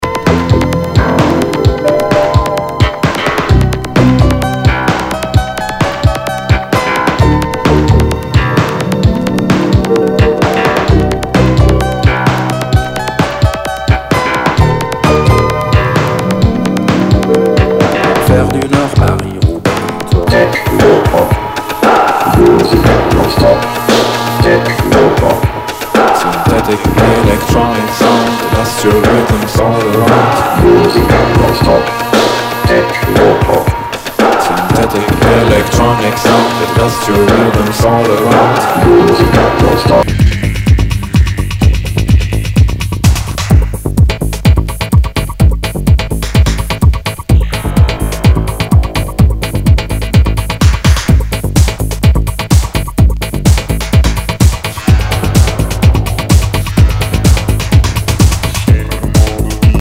HOUSE/TECHNO/ELECTRO
ナイス！シンセ・ポップ / テクノ・クラシック！！